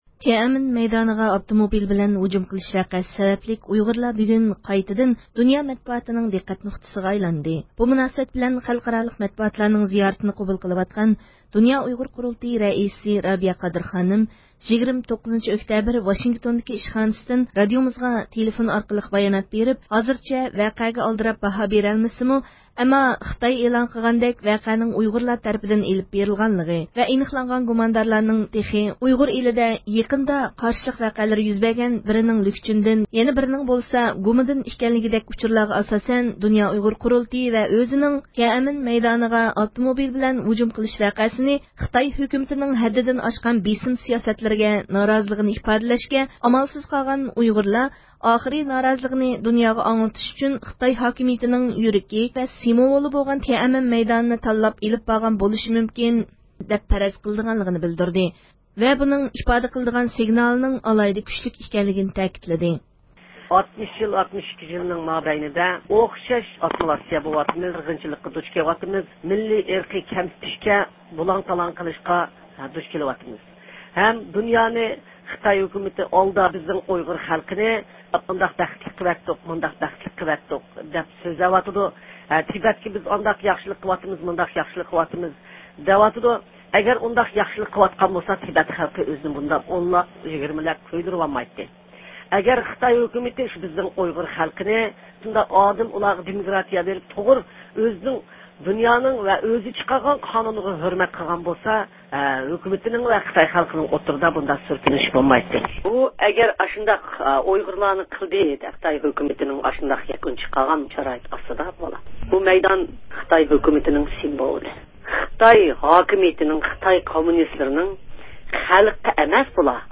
تيەنئەنمېن مەيدانىغا ئاپتوموبىل بىلەن ھۇجۇم قىلىش ۋەقەسى سەۋەبلىك ئۇيغۇرلار بۈگۈن قايتىدىن دۇنيا مەتبۇئاتىنىڭ دىققەت نۇقتىسىغا ئايلاندى، بۇ مۇناسىۋەت بىلەن خەلقئارالىق مەتبۇئاتلارنىڭ زىيارىتىنى قوبۇل قىلىۋاتقان دۇنيا ئۇيغۇر قۇرۇلتىيى رەئىسى رابىيە قادىر خانىم، 29- ئۆكتەبىر ۋاشىنگتوندىكى ئىشخانىسىدىن رادىئومىزغا تېلېفون ئارقىلىق بايانات بېرىپ، ھازىرچە ۋەقەگە ئالدىراپ باھا بېرەلمىسىمۇ، ئەمما خىتاي ئېلان قىلغاندەك ۋەقەنىڭ ئۇيغۇرلار تەرىپىدىن ئېلىپ بېرىلغانلىقى ۋە ئېنىقلانغان گۇماندارلارنىڭ بىرىنىڭ تېخى ئۇيغۇر ئېلىدا يېقىندا قارشىلىق ۋەقەلىرى يۈز بەرگەن لۈكچۈندىن، يەنە بىرىنىڭ بولسا گۇمىدىن ئىكەنلىكىدەك ئۇچۇرلارغا ئاساسەن، دۇنيا ئۇيغۇر قۇرۇلتىيى ۋە ئۆزىنىڭ، تيەنئەنمېن مەيدانىغا ئاپتوموبىل بىلەن ھۇجۇم قىلىش ۋەقەسىنى، خىتاي ھۆكۈمىتىنىڭ ھەددىدىن ئاشقان بېسىم سىياسەتلىرىگە نارازىلىقىنى ئىپادىلەشكە ئامالسىز قالغان ئۇيغۇرلار، ئاخىرى نارازىلىقىنى دۇنياغا ئاڭلىتىش ئۈچۈن خىتاي ھاكىمىيىتىنىڭ يۈرىكى ۋە سىمۋولى بولغان تيەنئەنمېن مەيدانىنى تاللاپ ئېلىپ بارغان بولۇشى مۇمكىن دەپ پەرەز قىلىدىغانلىقىنى بىلدۈردى ۋە بۇنىڭ ئىپادە قىلىدىغان سىگنالىنىڭ ئالاھىدە كۈچلۈك ئىكەنلىكىنى تەكىتلىدى.